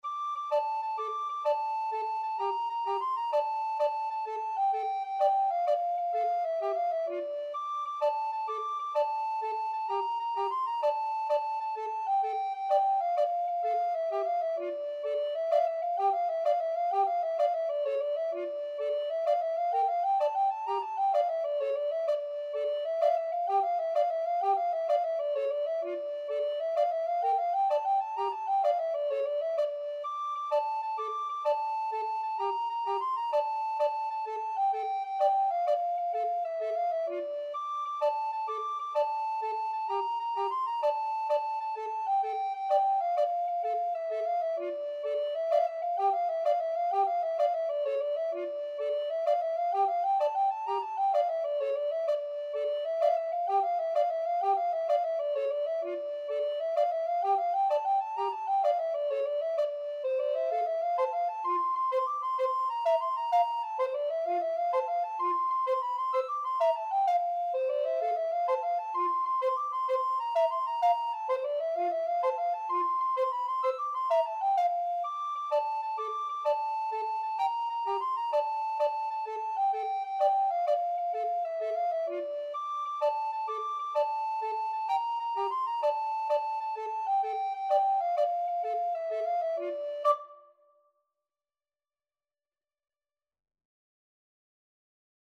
Free Sheet music for Alto Recorder Duet
Alto Recorder 1Alto Recorder 2
D minor (Sounding Pitch) (View more D minor Music for Alto Recorder Duet )
6/8 (View more 6/8 Music)
World (View more World Alto Recorder Duet Music)